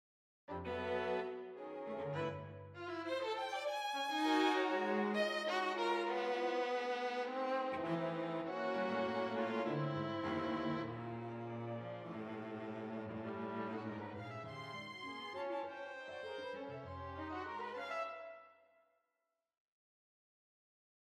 (sorry, poly legato was off when I exported the above, so cello bar 6 is partial)